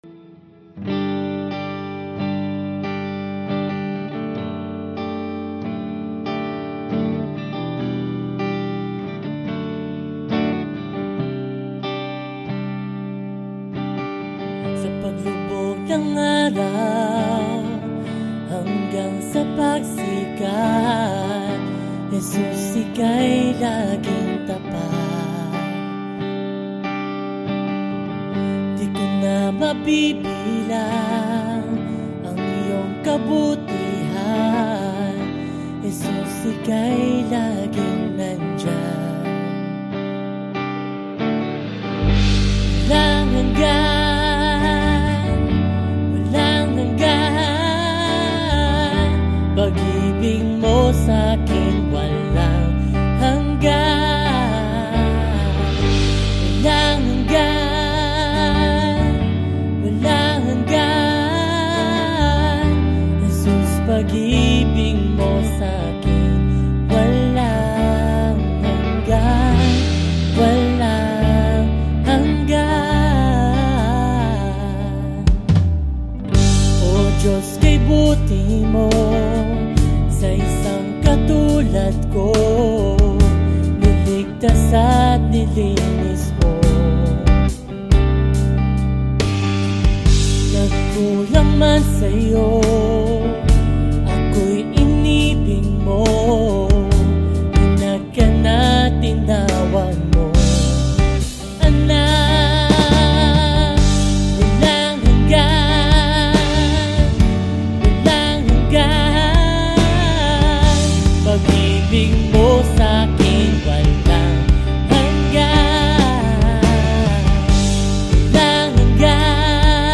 42 просмотра 51 прослушиваний 0 скачиваний BPM: 140